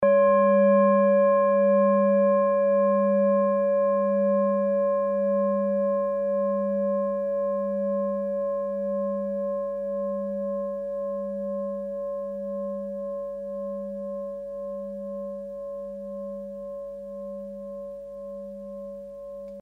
Klangschale Orissa Nr.24
Sie ist neu und wurde gezielt nach altem 7-Metalle-Rezept in Handarbeit gezogen und gehämmert.
Die Pi-Frequenz kann man bei 201,06 Hz hören. Sie liegt innerhalb unserer Tonleiter nahe beim "Gis".
klangschale-orissa-24.mp3